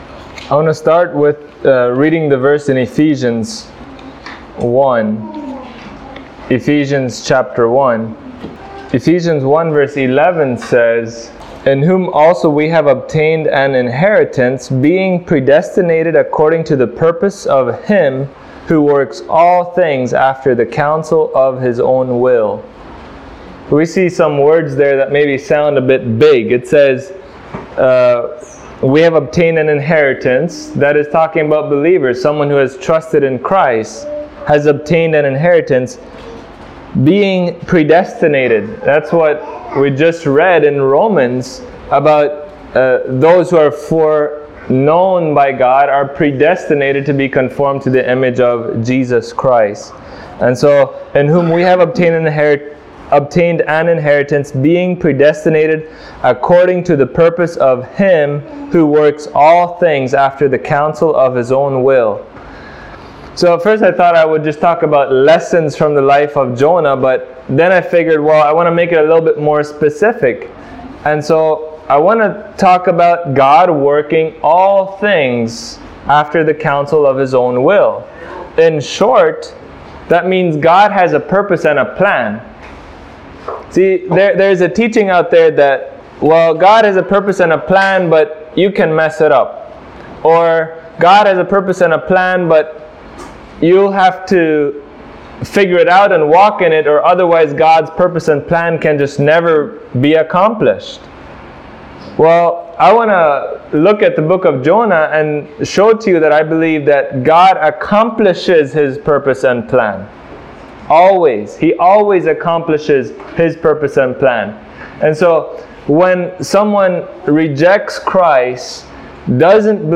Listen to this walk-through of the book of Jonah and learn about God’s sovereign character as revealed in that book. Sermon preached at Grace Baptist Church in Roaring Creek, Belize.